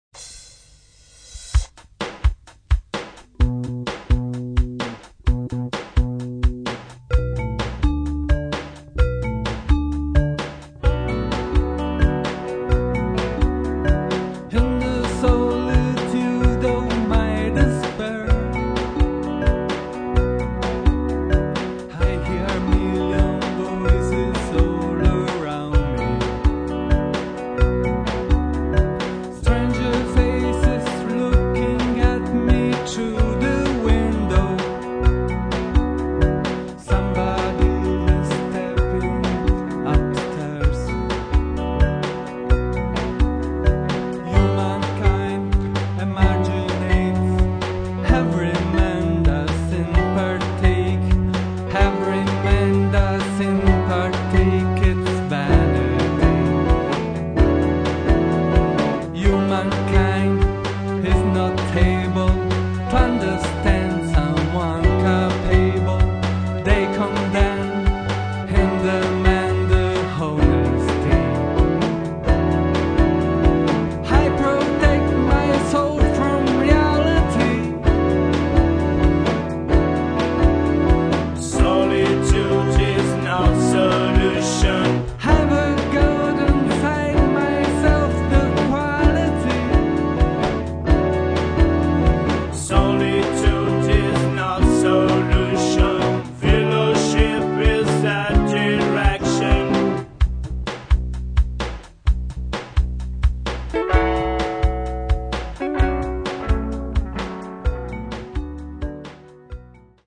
impegnati nel rock alternativo e nel rock dance
alternative rock and rock dance